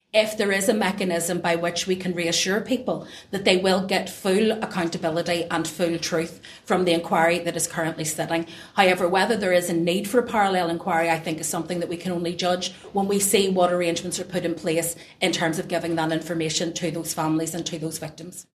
Stormont Justice Minister Naomi Long, says she would not want to put people unnecessarily through the process a second time………………